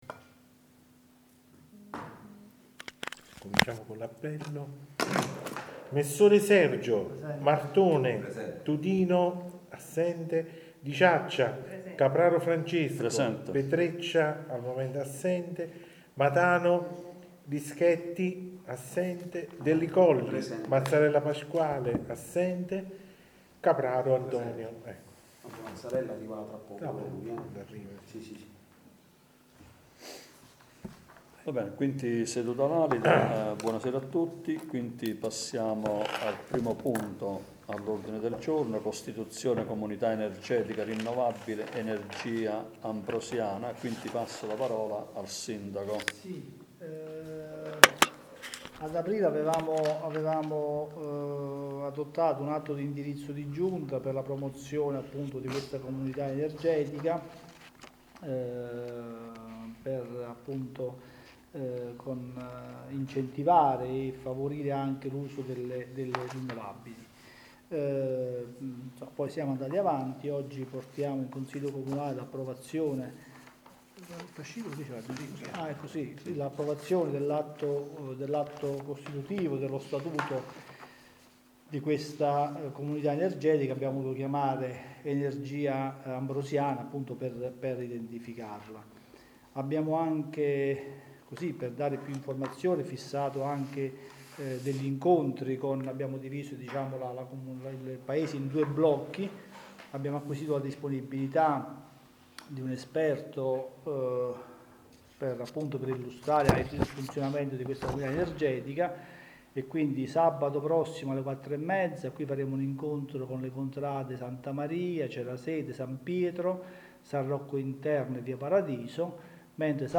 Registrazione seduta consiliare del 3.3.2025